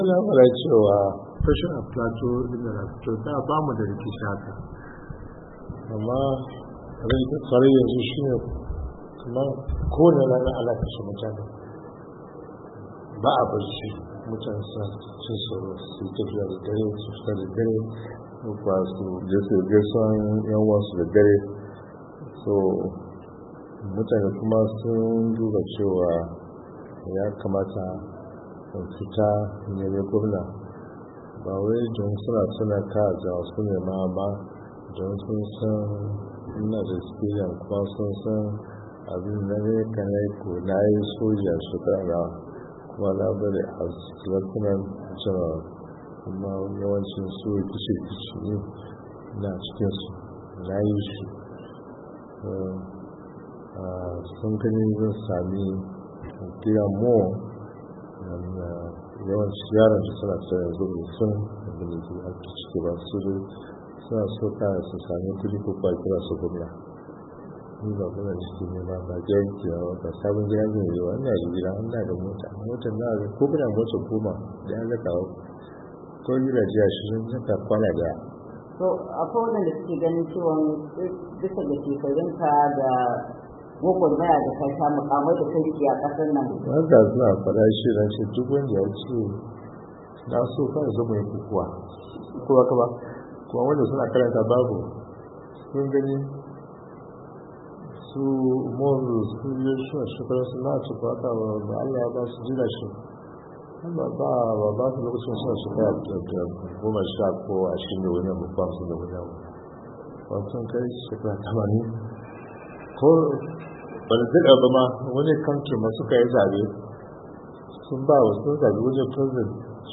Hira da J.T.Useni-3:30"